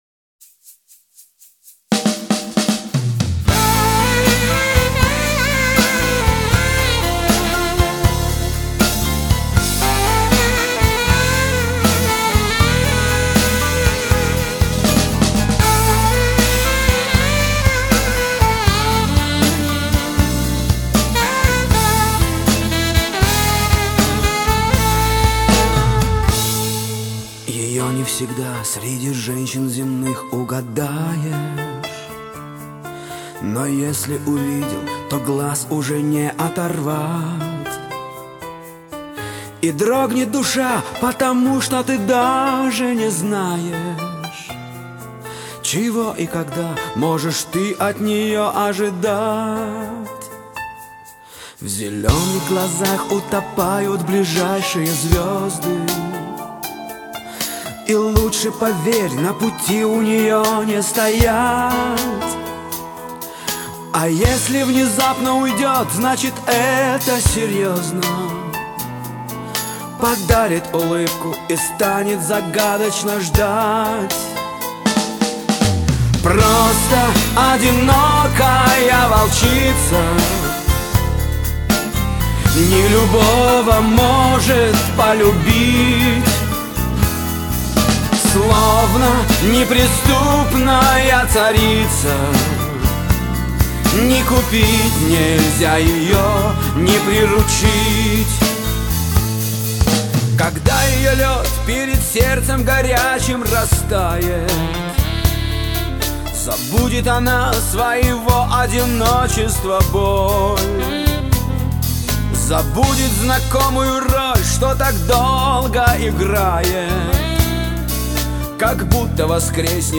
Красивейший инструментал!